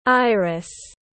Iris /ˈaɪ.rɪs/